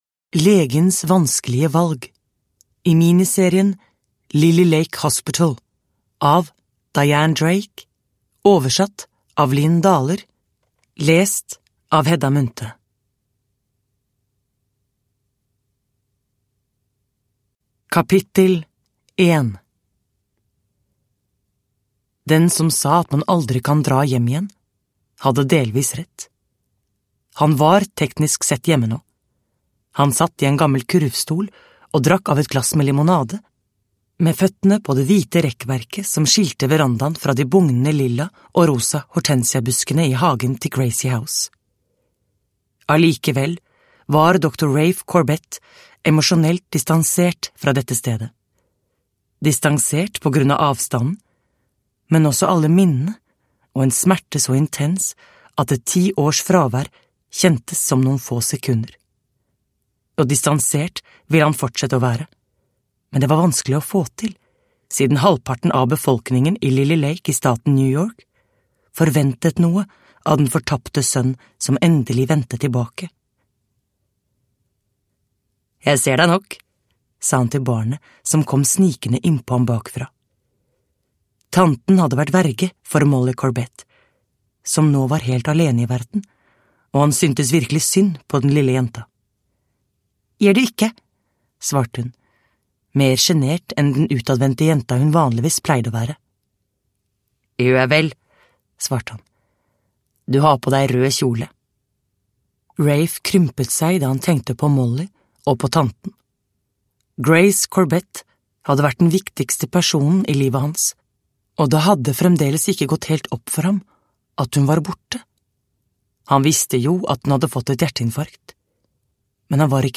Legens vanskelige valg – Ljudbok – Laddas ner